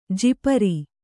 ♪ jipari